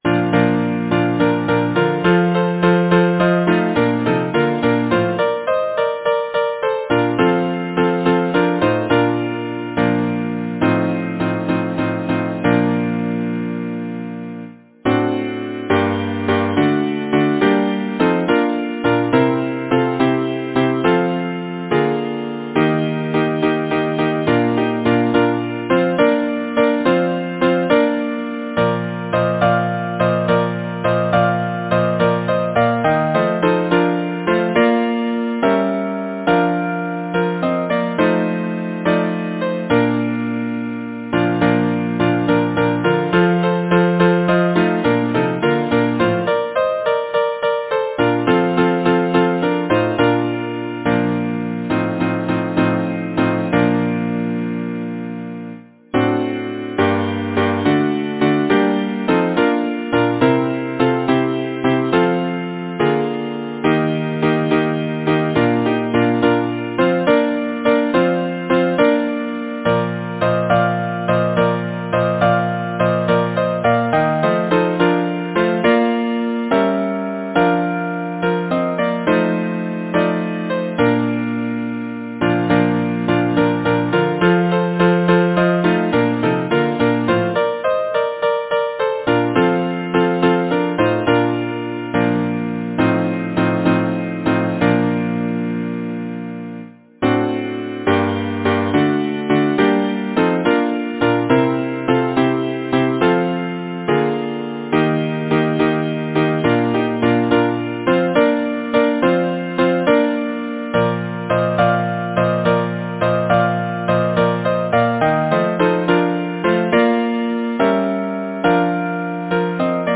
Title: The Spring is coming Composer: Simeon P. Cheney Lyricist: Number of voices: 4vv Voicing: SATB Genre: Secular, Partsong
Language: English Instruments: A cappella